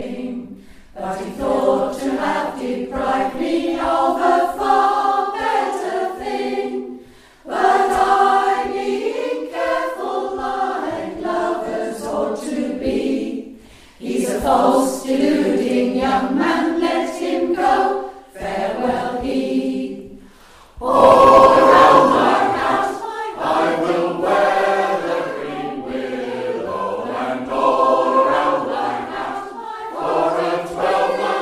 speaker-large.jpg England Trad. Folk